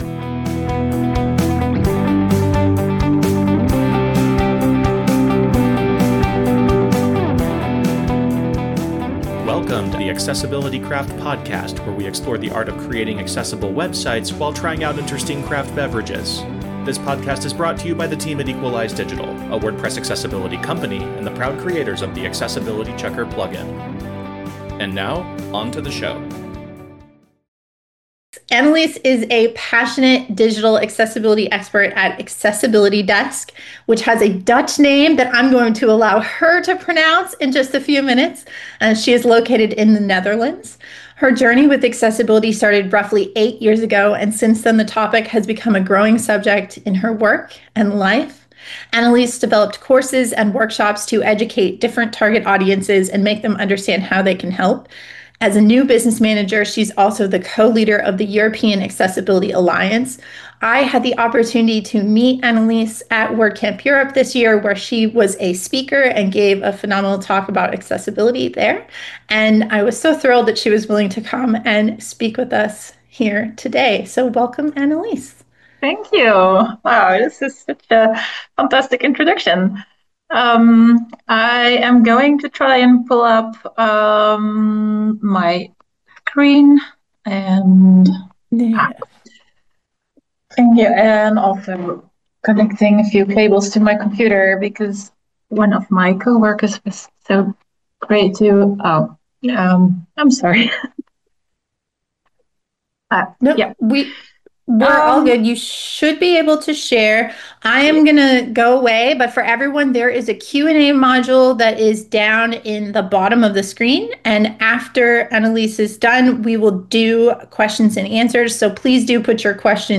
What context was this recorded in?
This episode is a recording of an October 2025 WordPress Accessibility Meetup where WordPress Accessibility Meetups take place via Zoom webinars twice a month, and anyone can attend.